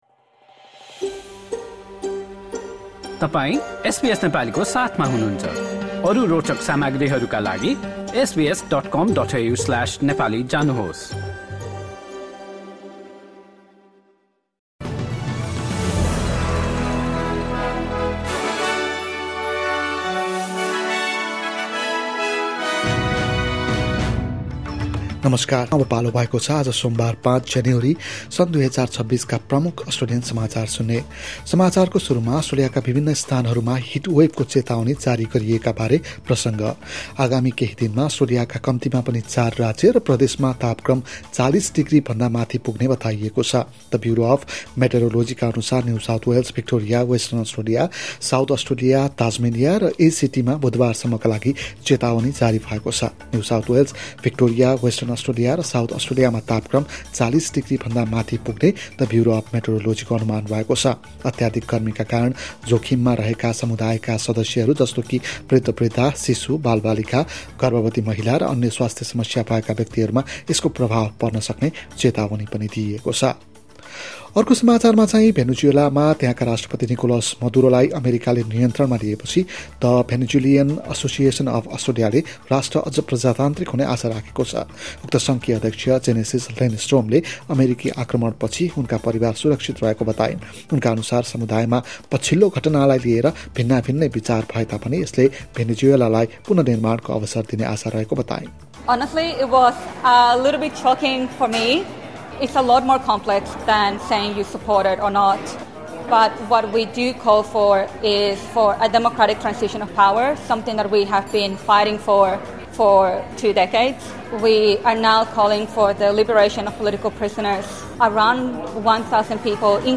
SBS Nepali Australian News Headlines: Monday, 5 January 2026